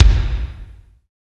Index of /90_sSampleCDs/Roland - Rhythm Section/KIT_Drum Kits 3/KIT_Pop Kit 1
KIK REVERB00.wav